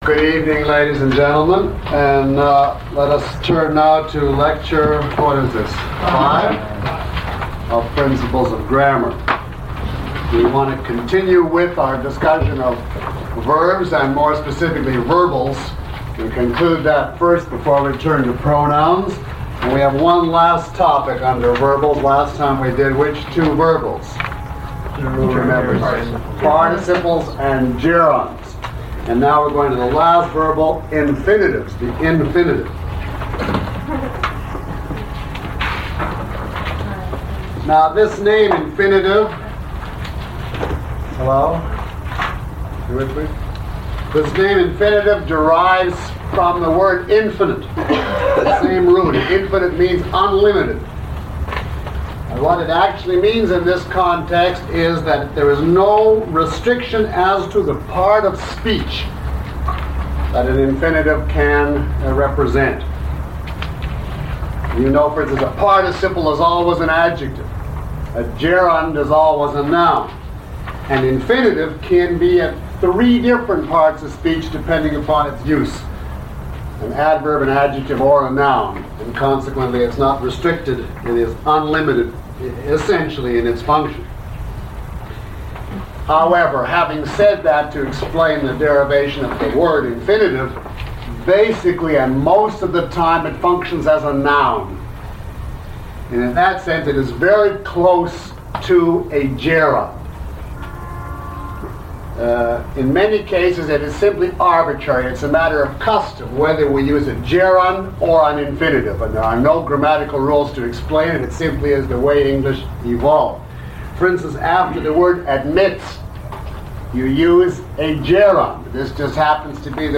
Lecture 05 - Principles of Grammar.mp3